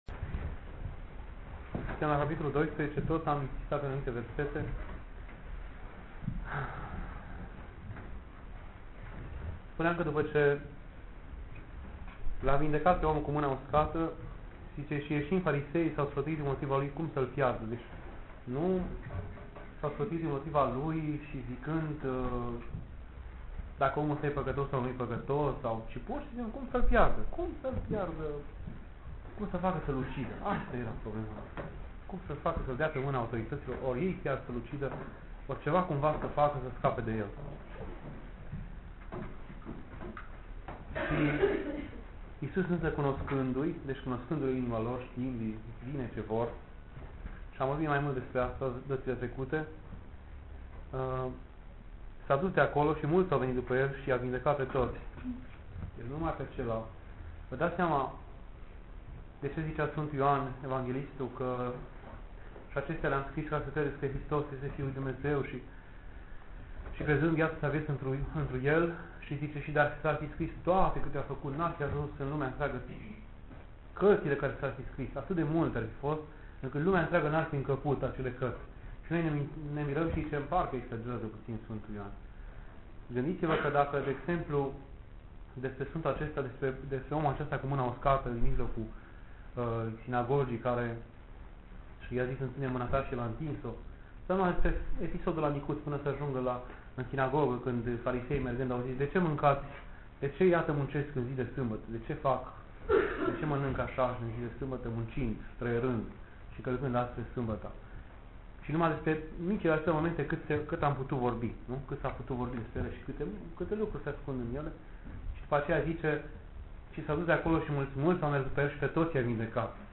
Discutii cu tinerii